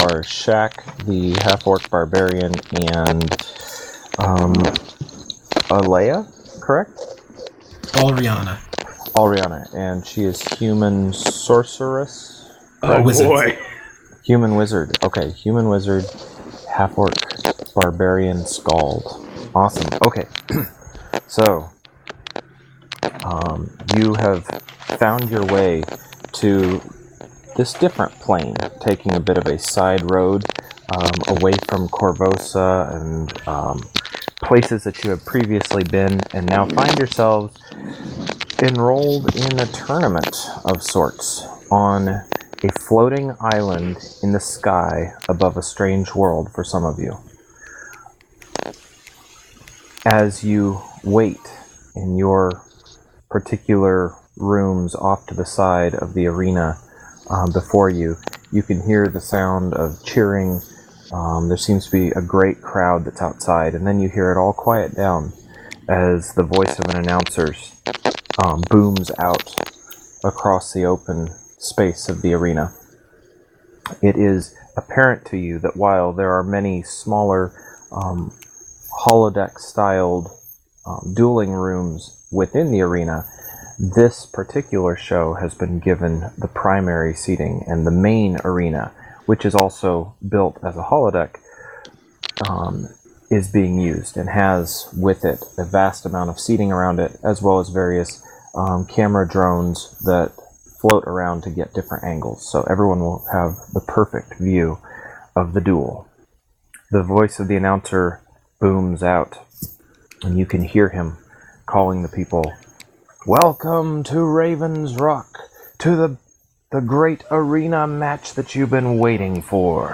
Matches: Xak vs Alryana; Tanis vs. Sindar; Alryana vs. Tanis -- note: some de-syncing in the audio